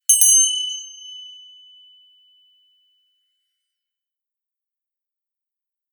熊よけ鈴の効果音
登山用に購入したので録音してみました。
チリリーンと綺麗な音が鳴ります。
和風効果音81.『熊よけ鈴①』